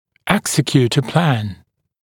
[‘eksɪkjuːt ə plæn] [-sək-][‘эксикйу:т э плэн] [-сэк-]осуществлять план, реализовывать план (напр. лечения)